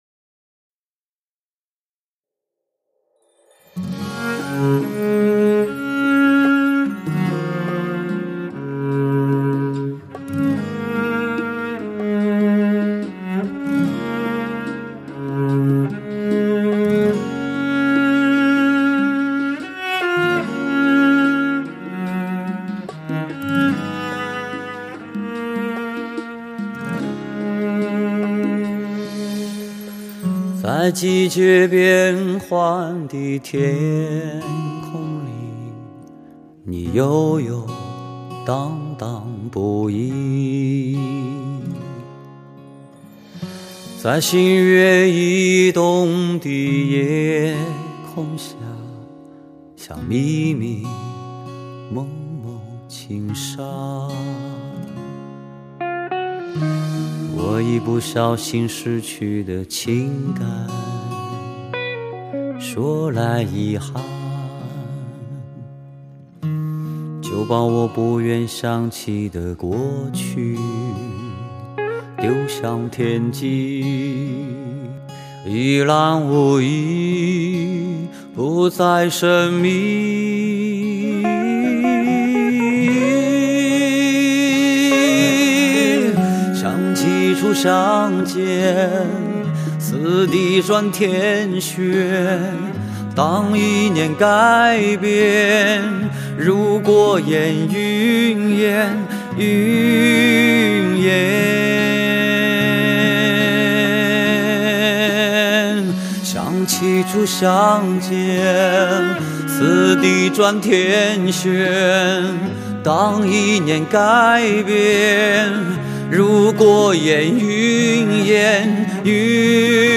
类型: 天籁人声
舒缓如流水般浸人心田的音乐节奏